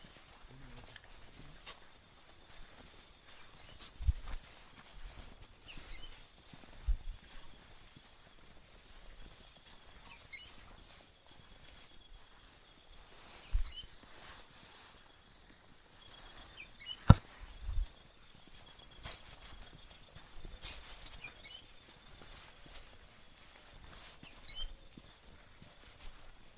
Pijuí Frente Gris (Synallaxis frontalis)
Nombre en inglés: Sooty-fronted Spinetail
Condición: Silvestre
Certeza: Observada, Vocalización Grabada
PIJUI-FRENTE-GRIS.mp3